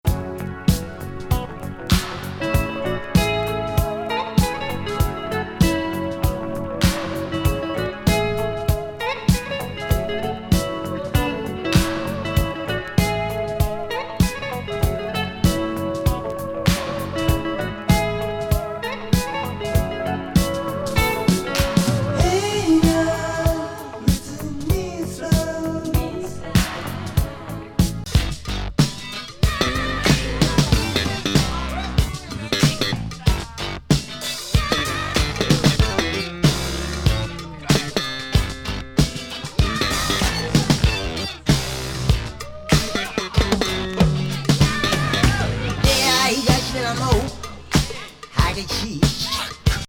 メロウ・グルーヴィン
チョッパーP-ファンキー
スペース・ファンク